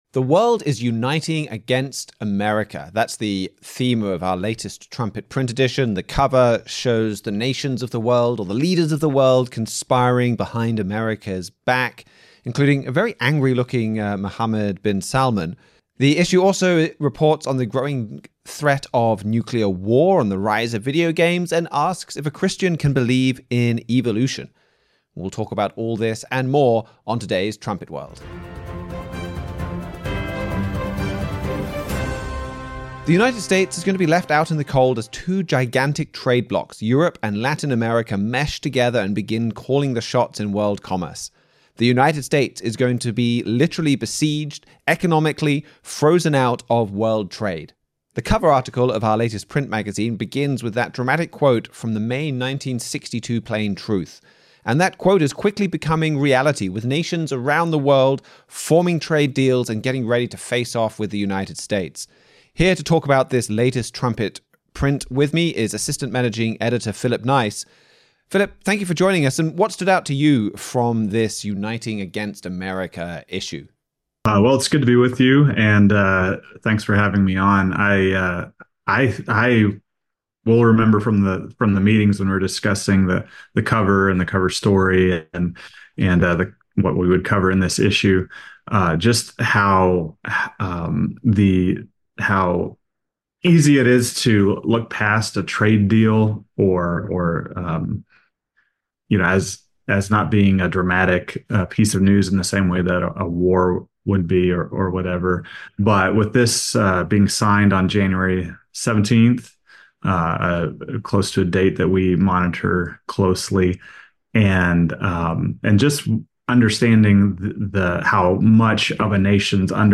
This issue highlights the growing rift between the United States and the rest of the world and where it will ultimately lead. The conversation includes discussion about feature articles and explains decades-long prophecies in the context of current events.